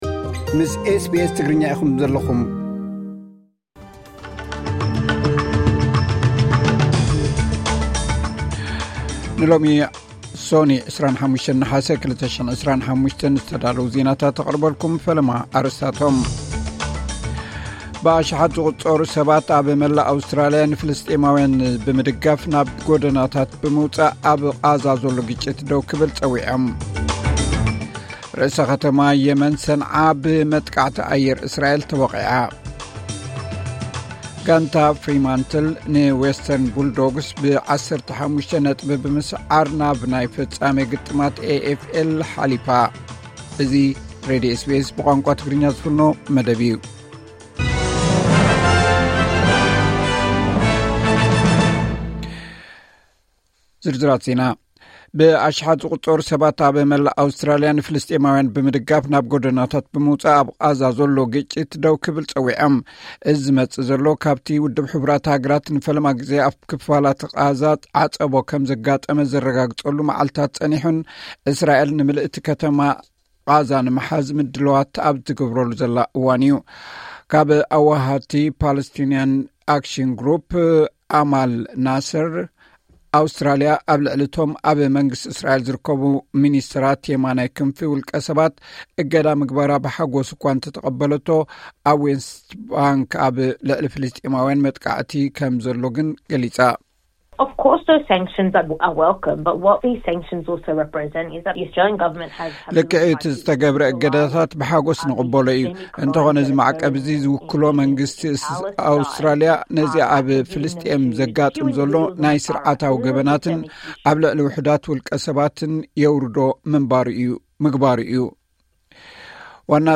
ዕለታዊ ዜና ኤስ ቢ ኤስ ትግርኛ (25 ነሓሰ 2025)